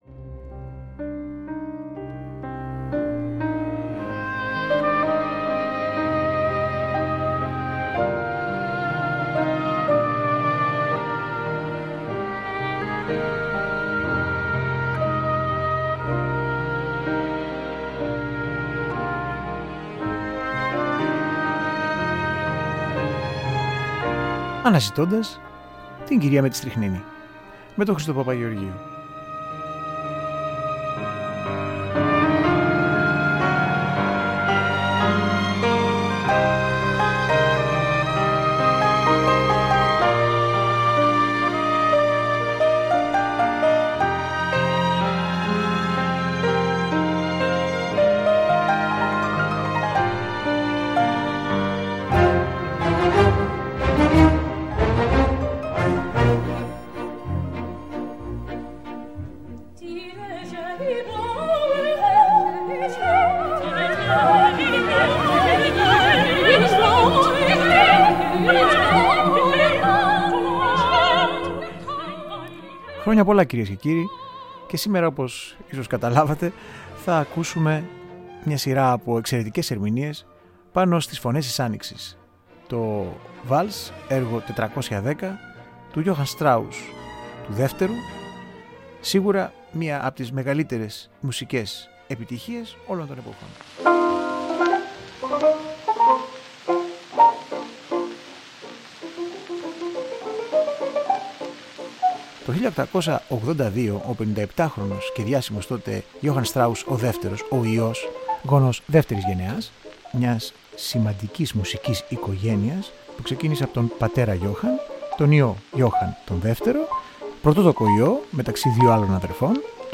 Συγκριτικες ακροασεις